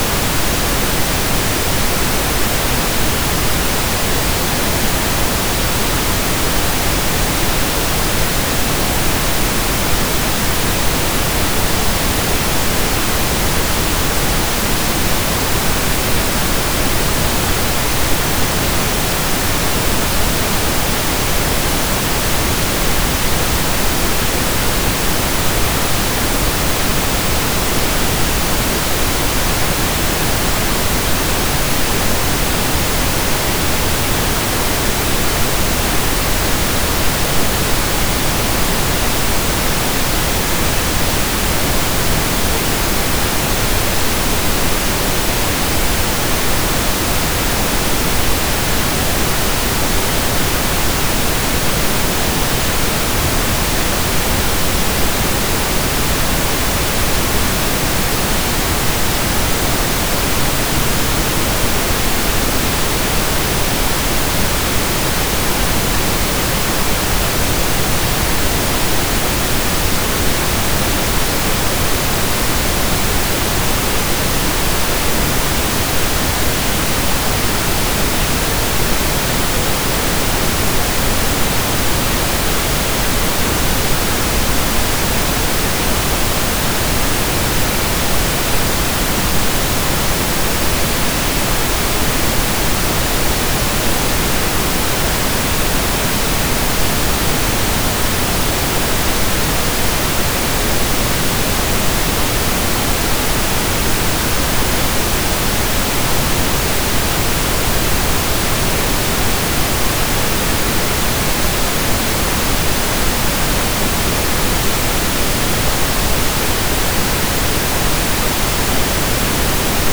Pink Noise 16-bit 44.1kHz.flac